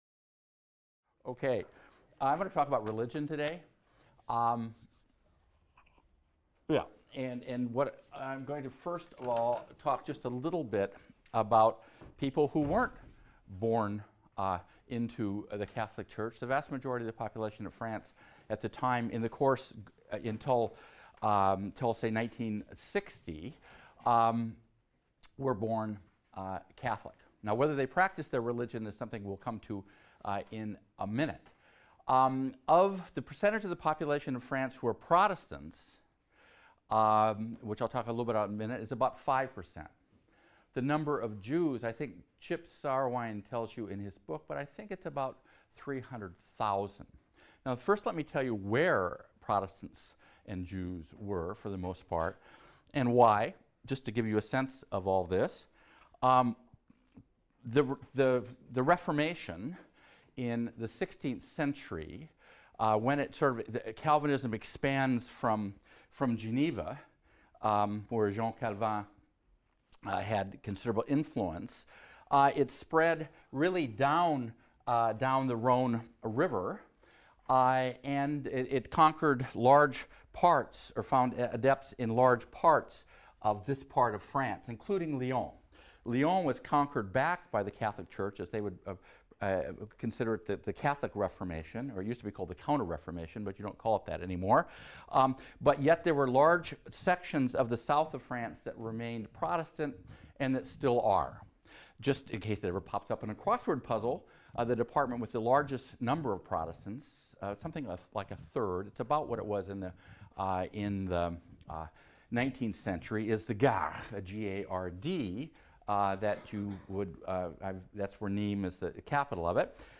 HIST 276 - Lecture 5 - The Waning of Religious Authority | Open Yale Courses